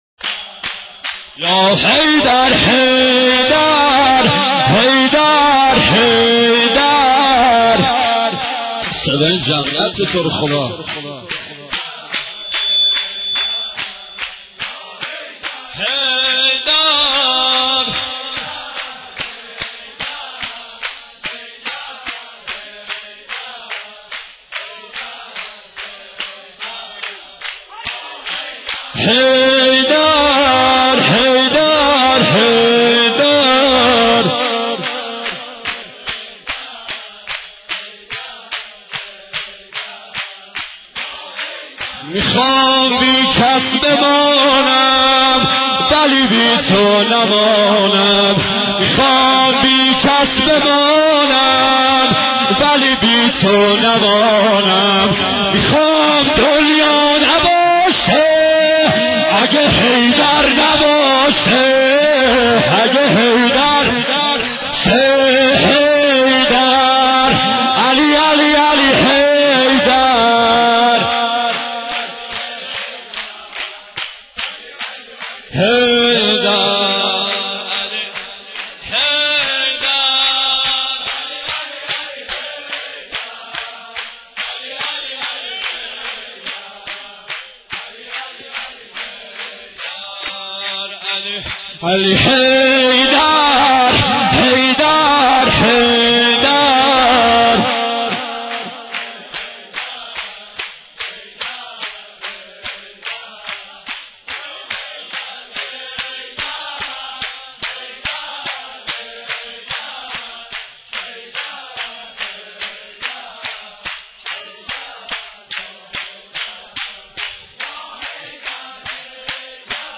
گلچین مداحی های ویژه مبعث پیامبر اکرم(ص)